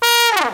Index of /m8-backup/M8/Samples/FAIRLIGHT CMI IIX/BRASS1